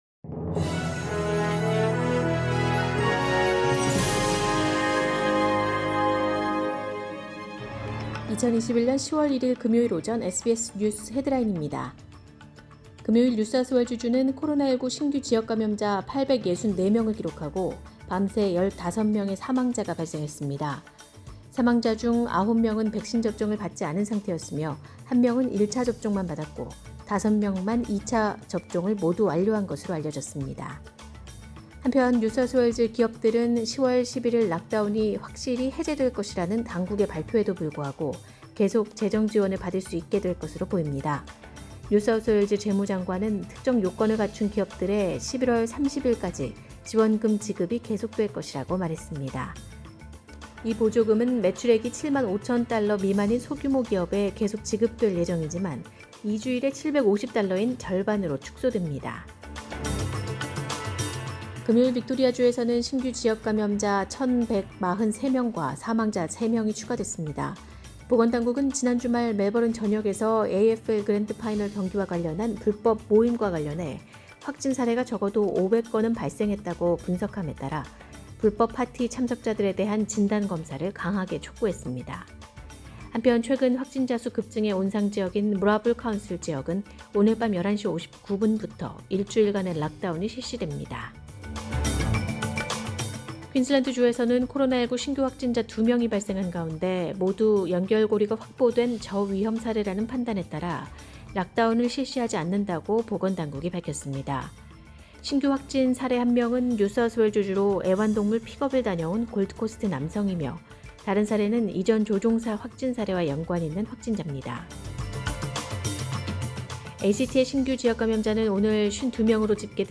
“SBS News Headlines” 2021년 10월 1일 오전 주요 뉴스
2021년 10월 1일 금요일 오전의 SBS 뉴스 헤드라인입니다.